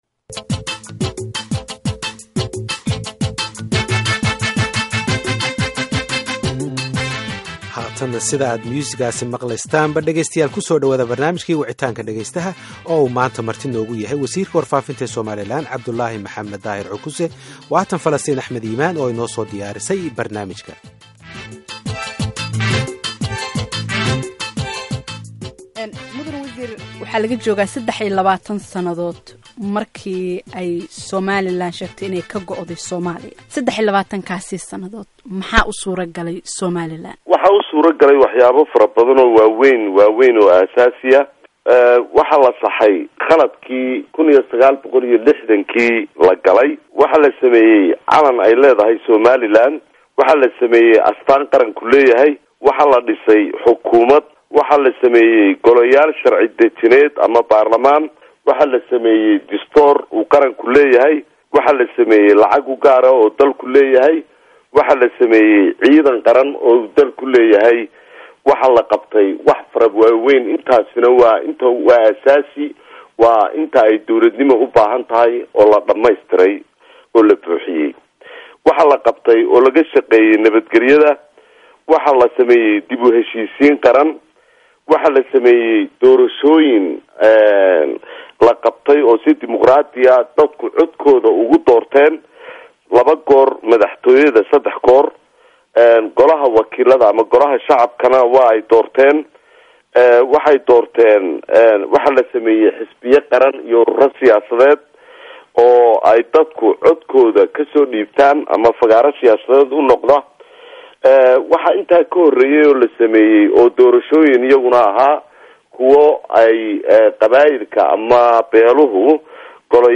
Barnaamijka Wicitaanka Dhageystaha ee toddobaadkan waxa marti ku ahaa Wasiirka Warfaafinta Somaliland, Cabdillaahi Maxamed Daahir (Cukuse) oo ka jawaabaya su'aalo la xiriira gooni isu-taagga Somaliland.